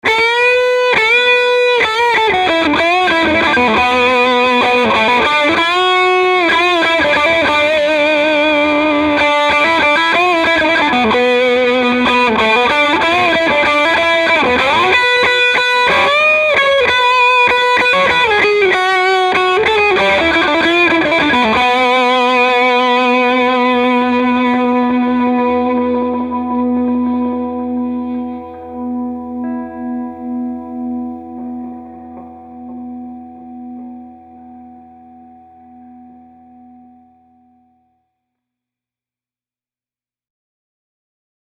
Dirty – Bridge Pickup
Clean clips were played in the Clean channel of my Fender Hot Rod Deluxe, while the dirty clips were played in the Drive channel. For the dirty treble pickup clip, I slammed the front-end of the amp with a wide-open Creation Audio Labs Mk.4.23 clean boost, the best clean boost on the planet. That brought on tons of overtones and rich harmonics!
dirty-treble.mp3